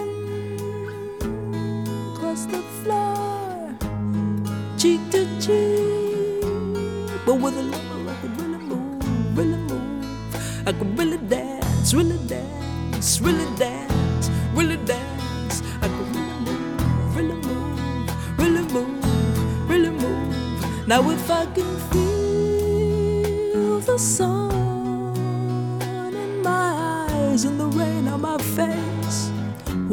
• Pop
alto saxophone